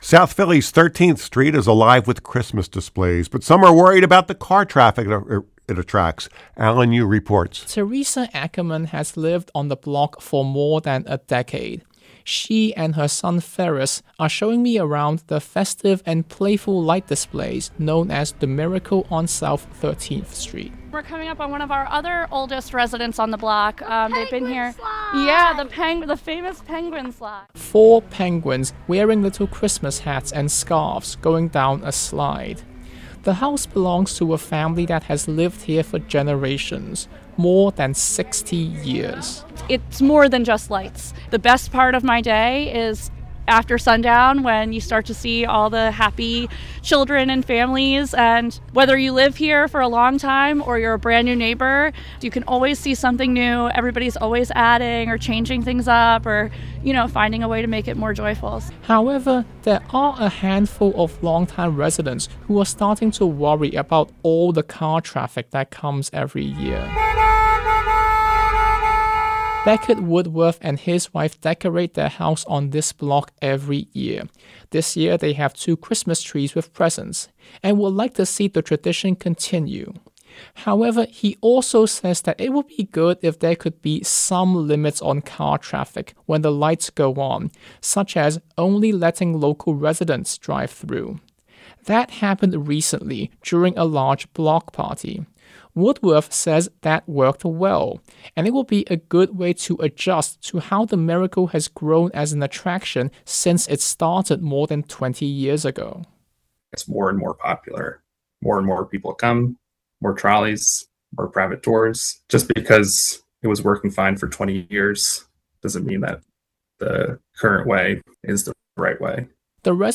When the Mormon Tabernacle Choir came to the Mann Center in Philadelphia Thursday night, several local singers won a chance to sit in with one of the world’s most famous choral groups.